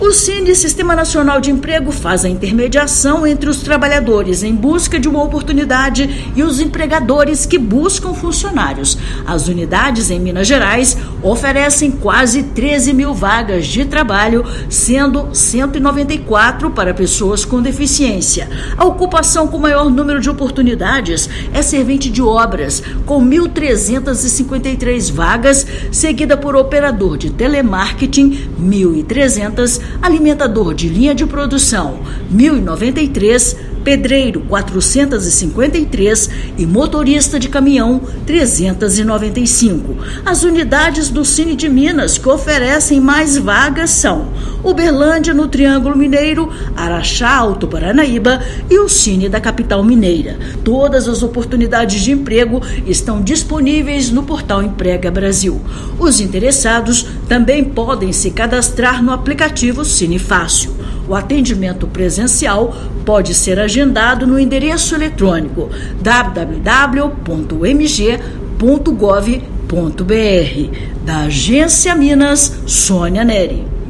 Informações são do Painel de Informações sobre o sistema, administrado pela Sedese. Ouça matéria de rádio.